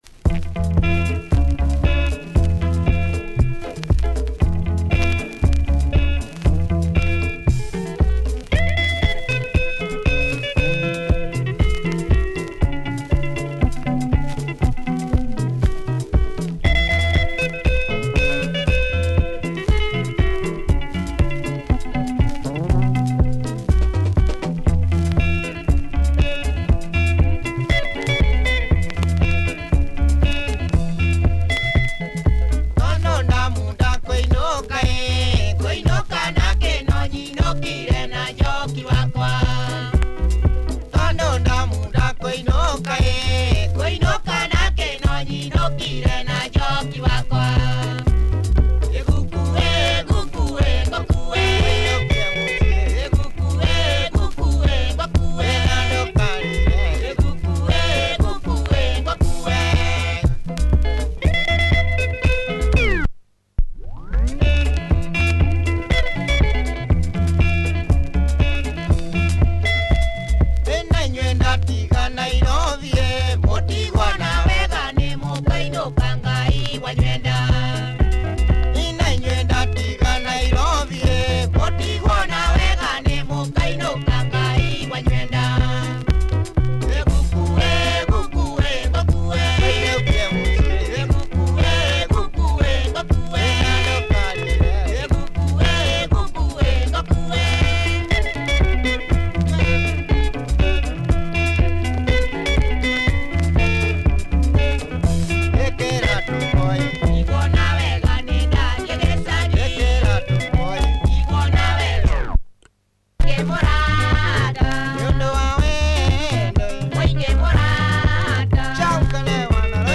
Nice driving Kikuyu Benga.